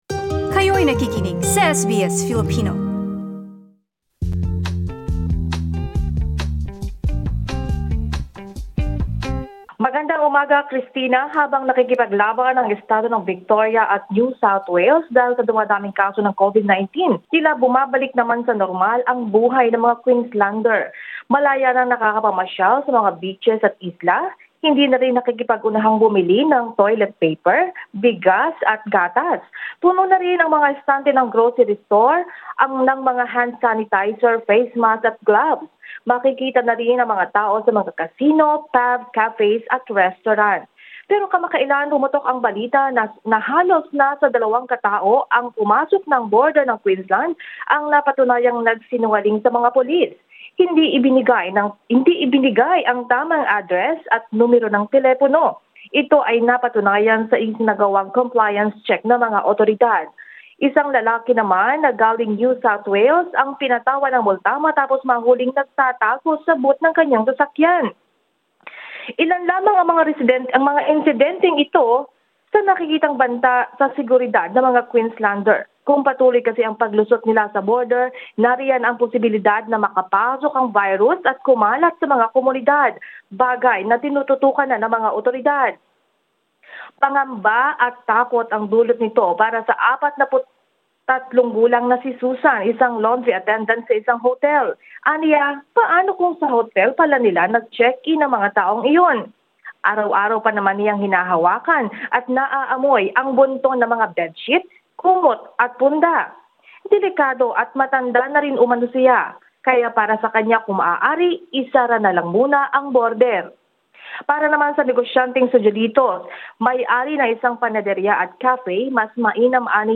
Sa ngayon, mahigpit pa ring pinapatupad ang 14 na araw na hotel quarantine para sa mga Queenslander na babalik sa Sunshine state na dumalaw o pumunta sa kahit na anong hotspot sa NSW. Pakinggan ang report.